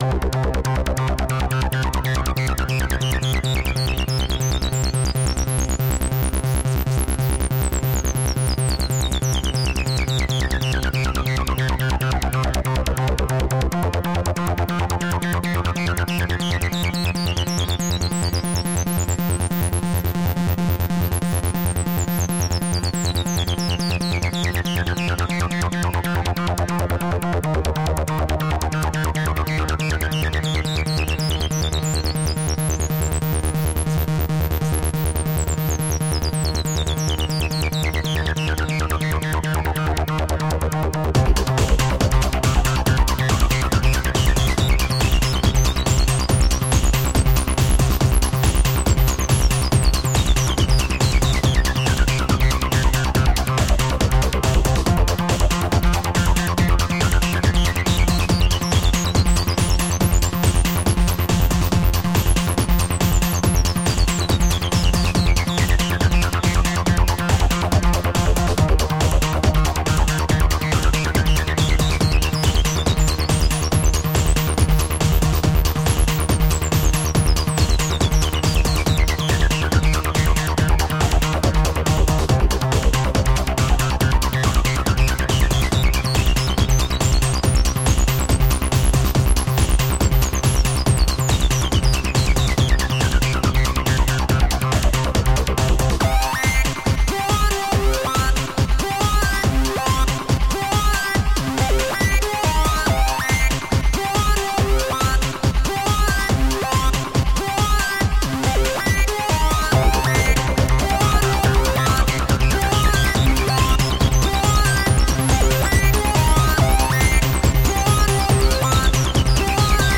Upbeat underground with shades of new wave.
Tagged as: Electronica, Techno, IDM